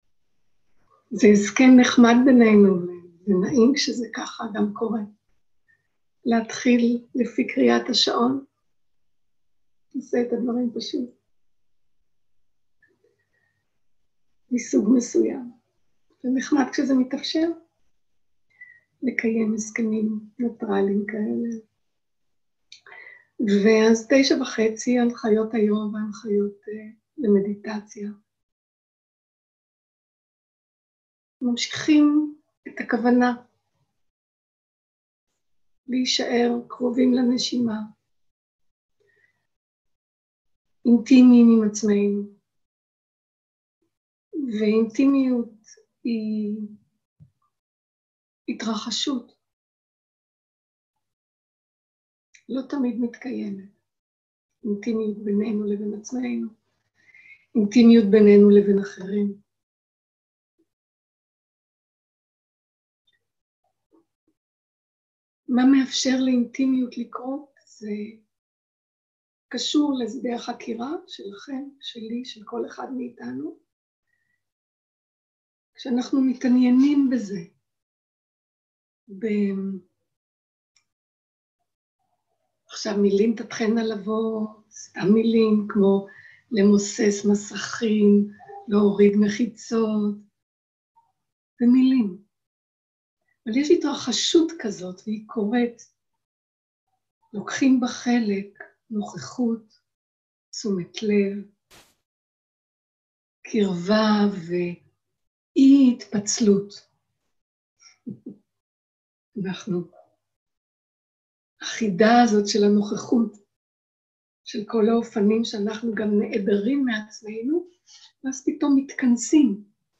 שיחת דהרמה
Dharma type: Dharma Talks שפת ההקלטה